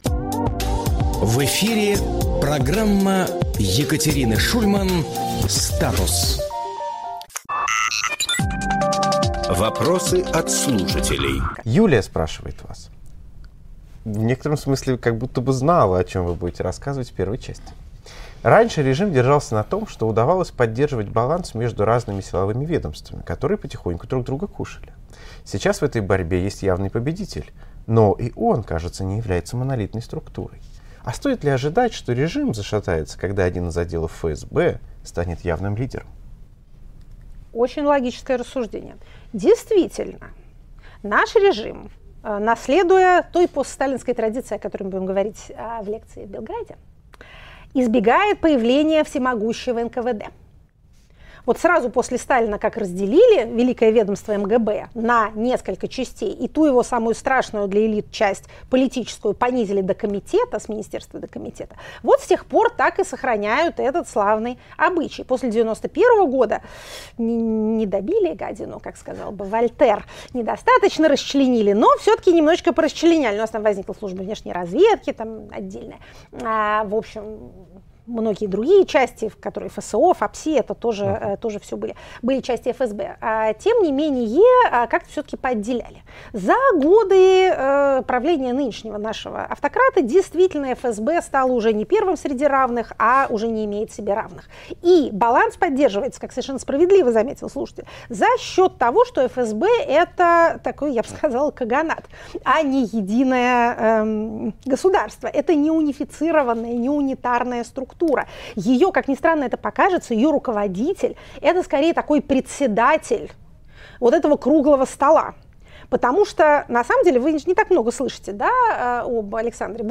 Екатерина Шульманполитолог
Фрагмент эфира от 03.06.25